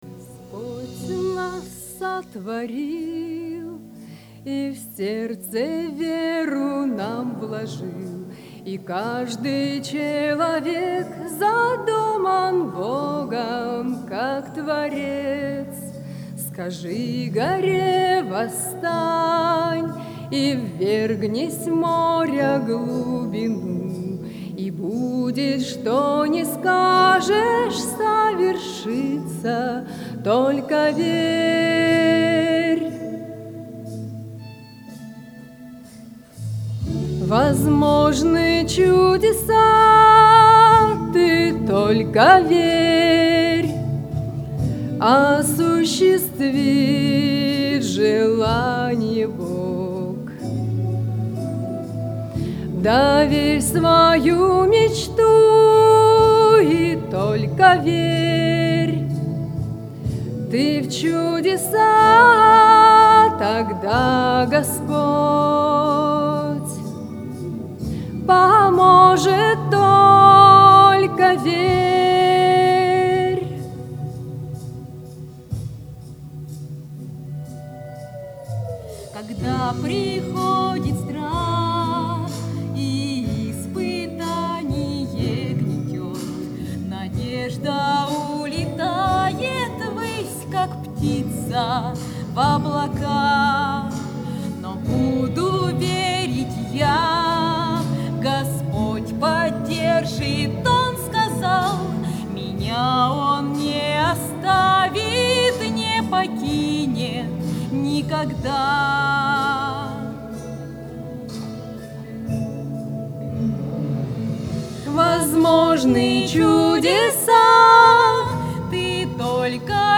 on 2015-09-19 - Христианские песни